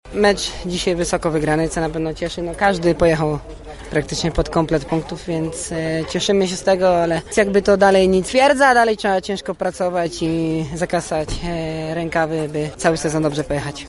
– podsumował kapitan lubelskiej drużyny Bartosz Zmarzlik